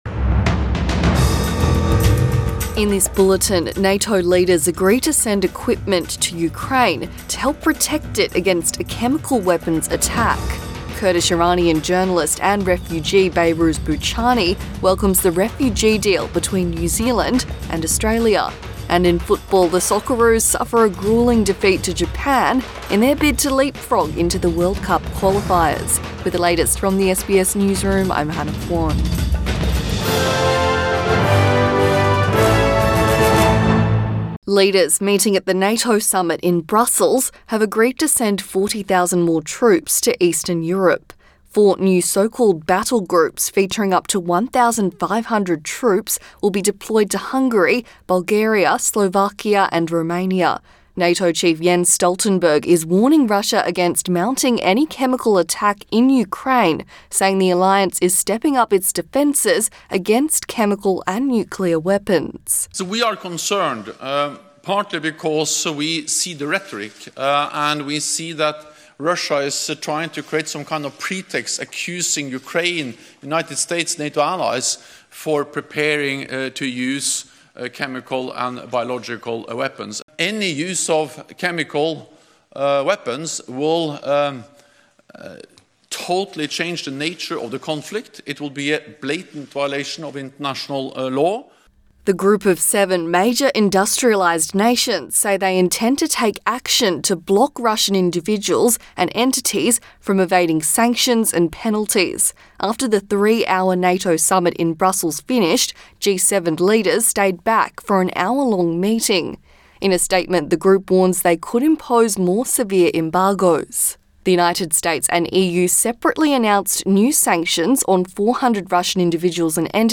AM bulletin 25 March 2022